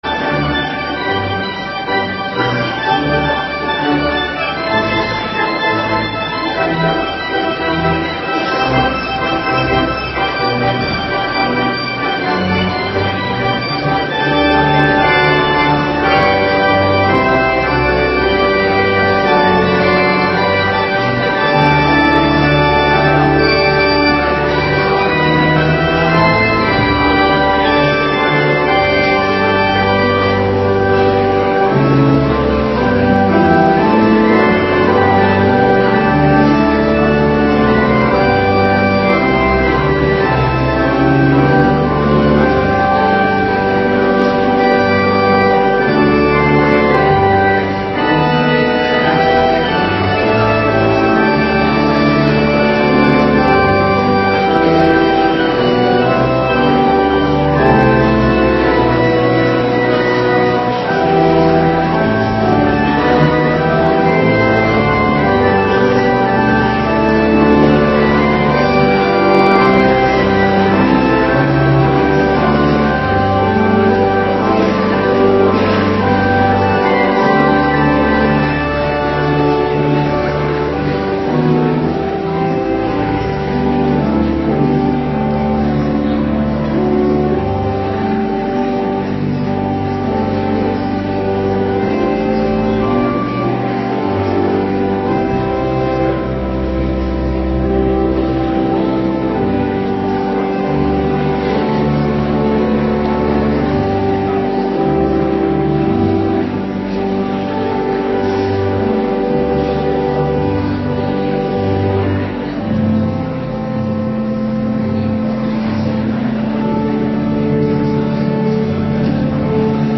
Morgendienst 5 april 2026